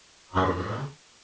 speech-commands